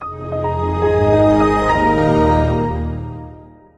windowsXP_startup.ogg